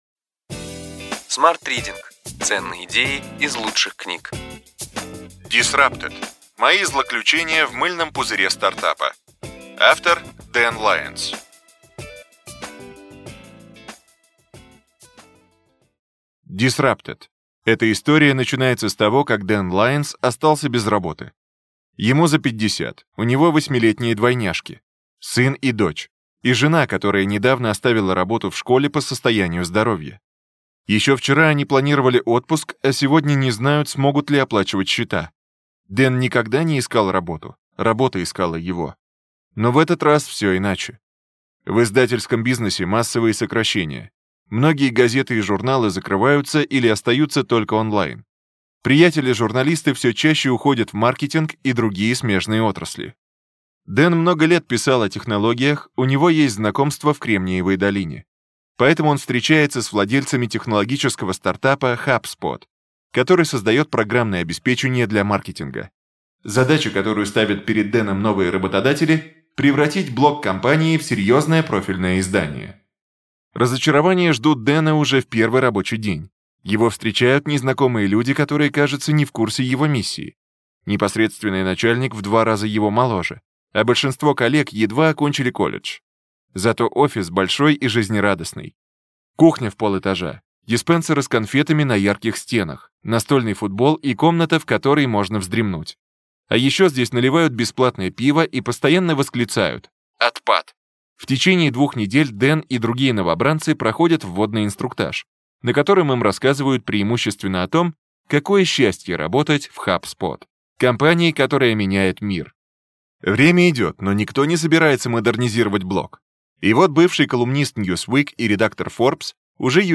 Аудиокнига Ключевые идеи книги: Disrupted: мои злоключения в мыльном пузыре стартапа. Дэн Лайонс | Библиотека аудиокниг